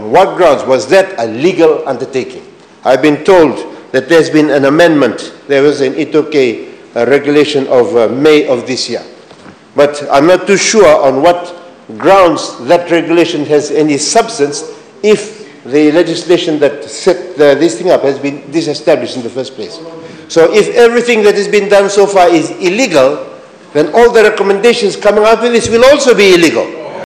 Minister of iTaukei Affairs Ifereimi Vasu provided the progress update on the GCC in parliament today.
While responding to the Minister, Usamate states that there were no laws in place allowing the GCC to convene.